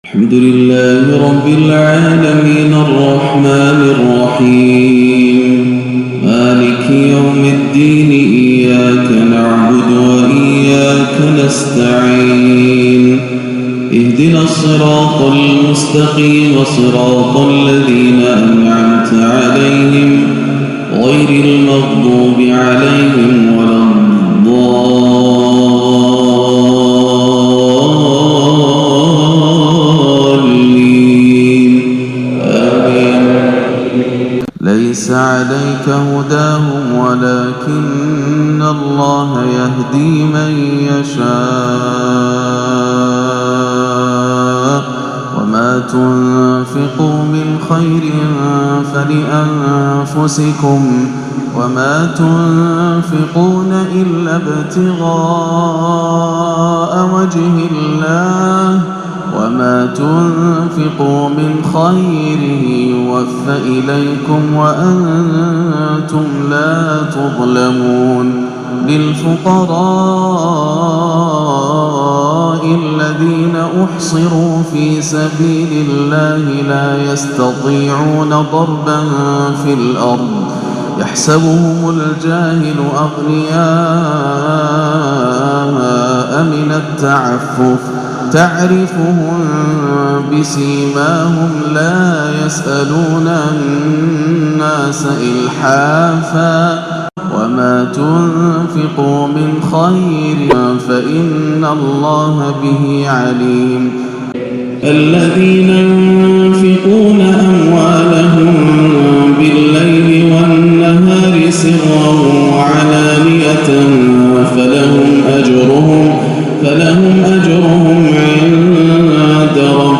صلاة الفجر 6-4-1439هـ ماتيسر من سورة البقرة 272-281 > عام 1439 > الفروض - تلاوات ياسر الدوسري